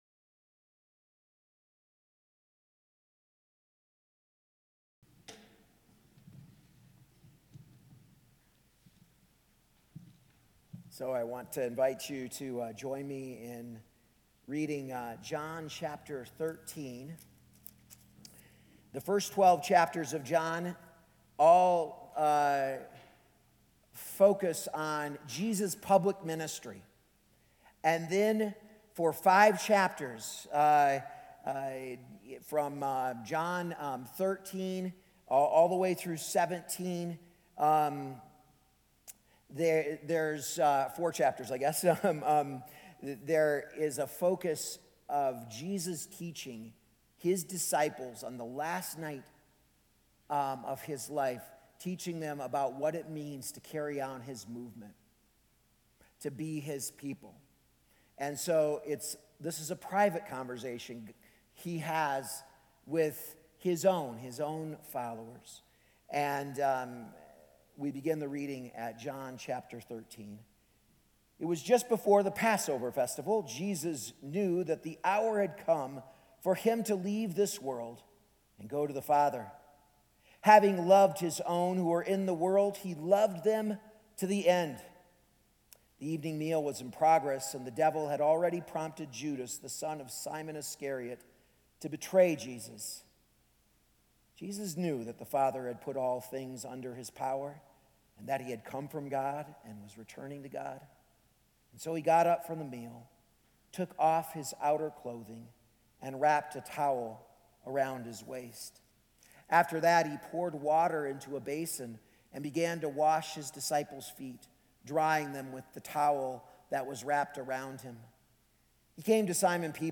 A message from the series "Encountering the Cross."